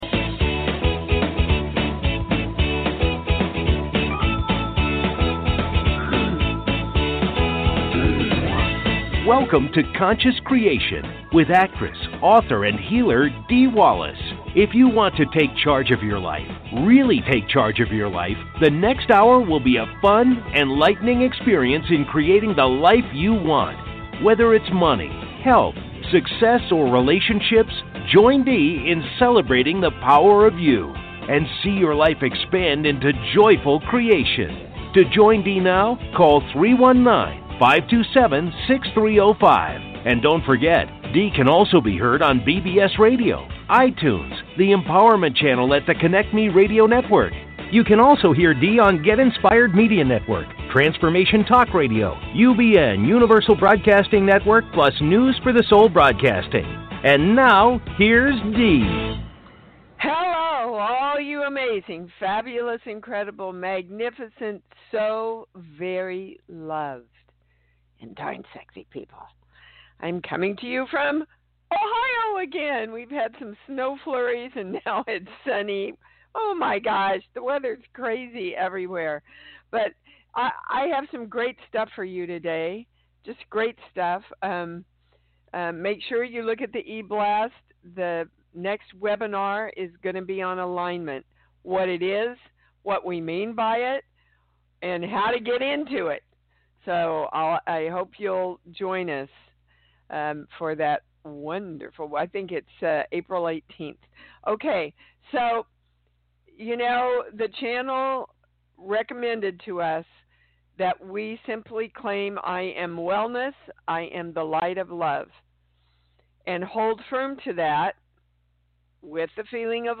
Talk Show Episode, Audio Podcast, Conscious Creation and with Dee Wallace on , show guests , about Dee Wallace,Spiritual Readings,Core Truths,Balanced Life,Energy Shifts,Spiritual Memoir,Healing Words,Consciousness,Self Healing,Teaching Seminars, categorized as Courses & Training,Kids & Family,Paranormal,Philosophy,Motivational,Spiritual,Access Consciousness,Medium & Channeling,Psychic & Intuitive